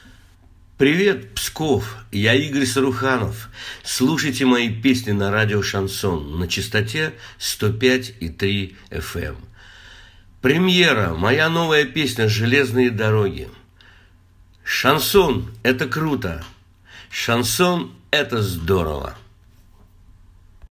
Певец Игорь Саруханов поздравил радио «Шансон» с началом вещания в Пскове.